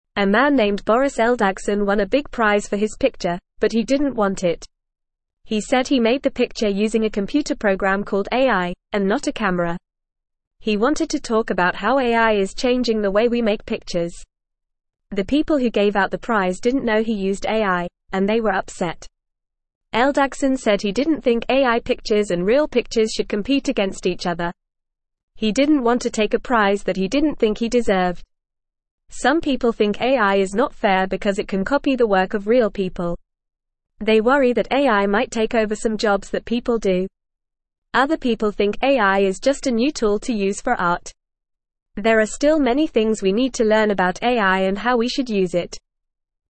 Fast
English-Newsroom-Beginner-FAST-Reading-Man-Wins-Picture-Prize-Gives-It-Back.mp3